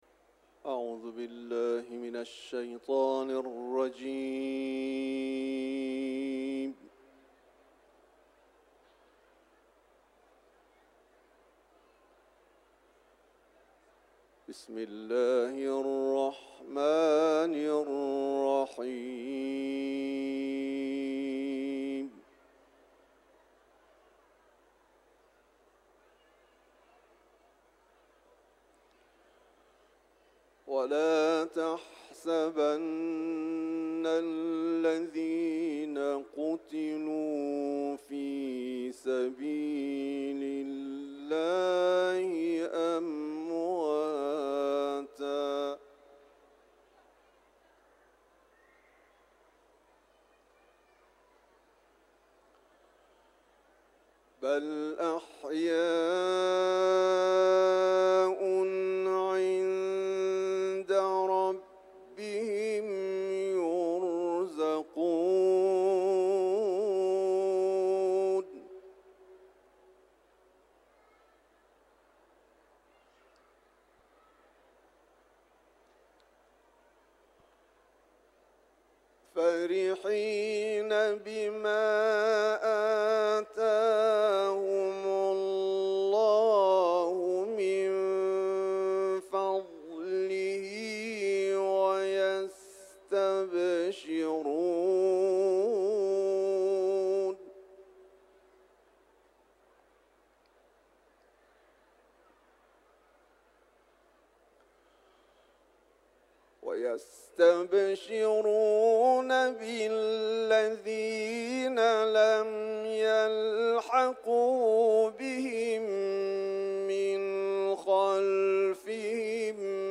حرم مطهر رضوی ، سوره آل عمران